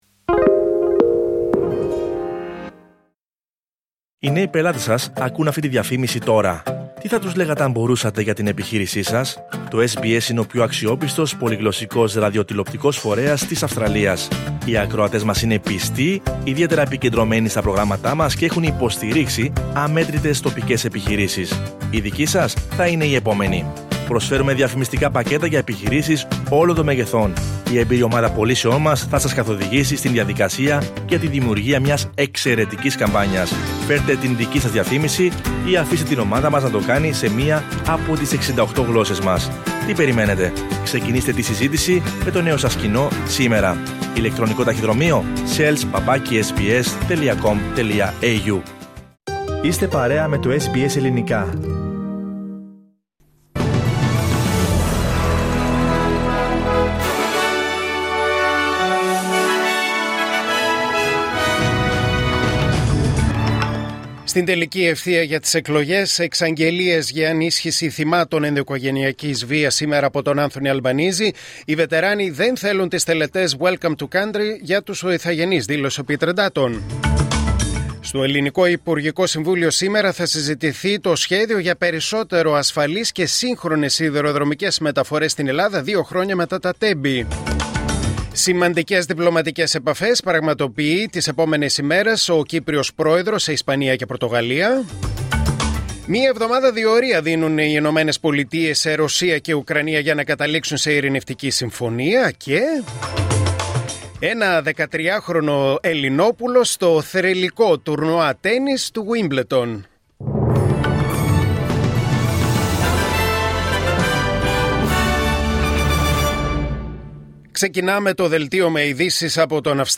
Δελτίο Ειδήσεων Δευτέρα 28 Απριλίου 2025